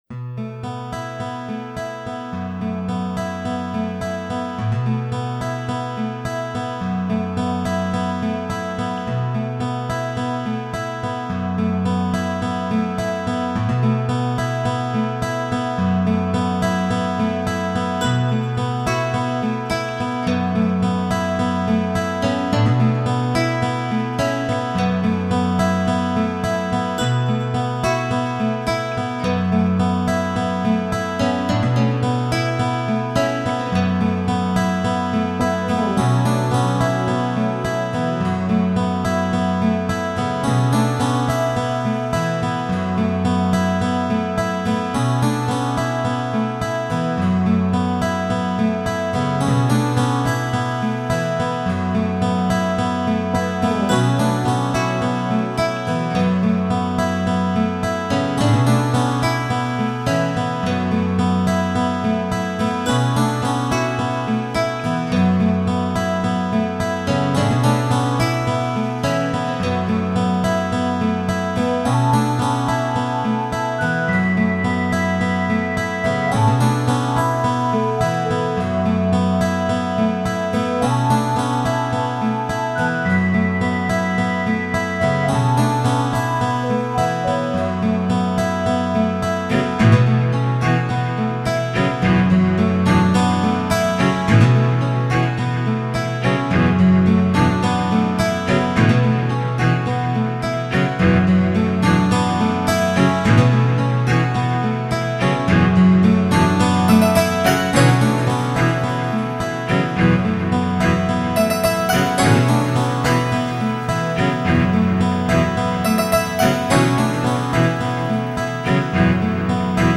dance/electronic
laid-back acoustic trip ... time to mellow out
New age